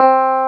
CLAV2SFTC4.wav